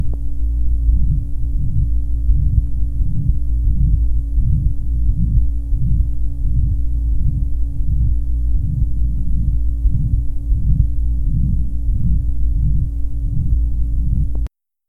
Date 1969 Type Systolic Abnormality Ventricular Septal Defect 4 year old with large flow VSD (good localizing) To listen, click on the link below.